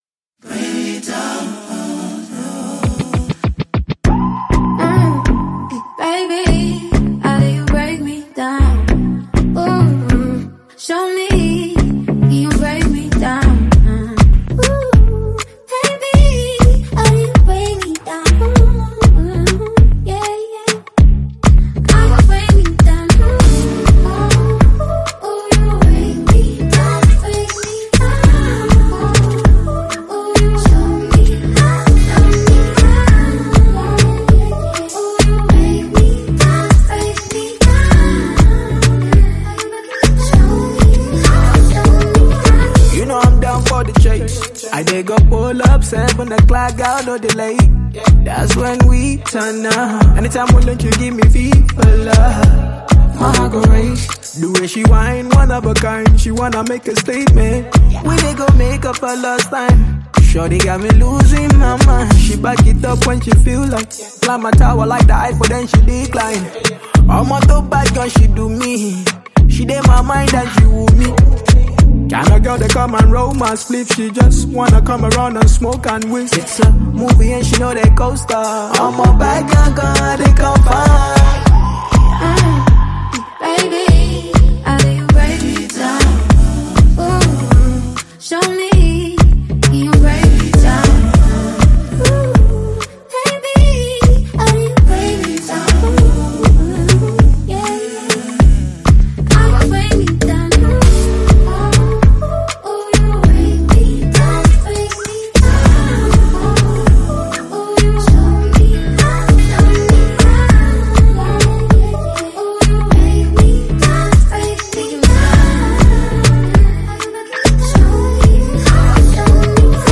Nigerian singer-songwriter
ballad